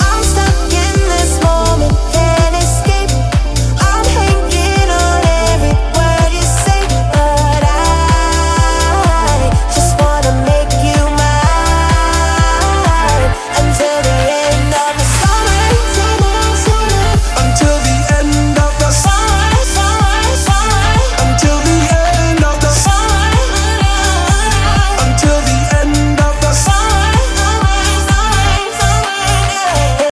танцевальная